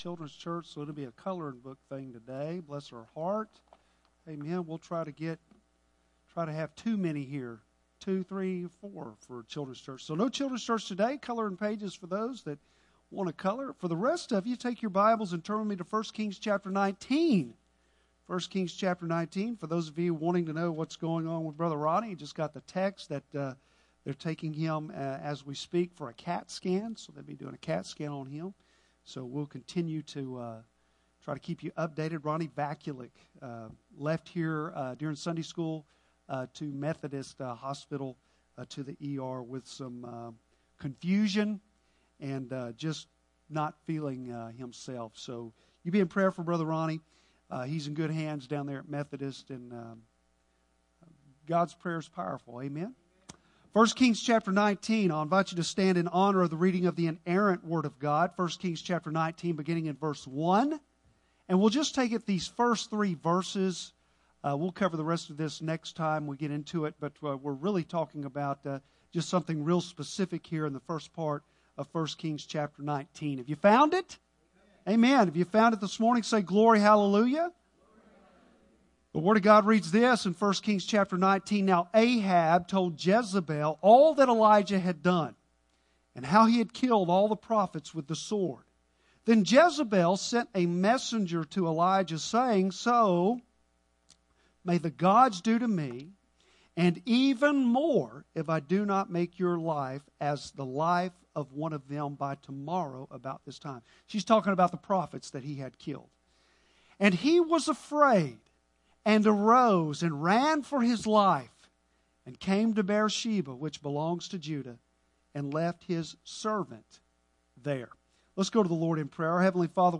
Bible Text: I Kings 19:1-3 | Preacher